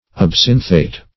Search Result for " absinthate" : The Collaborative International Dictionary of English v.0.48: Absinthate \Ab"sin"thate\ ([a^]b*s[i^]n"th[asl]t), n. (Chem.) A combination of absinthic acid with a base or positive radical.